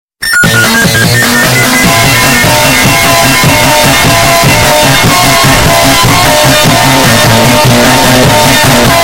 Sus gotta go fart sound button is a short, punchy audio clip that people love using in memes, gaming streams, and reaction edits.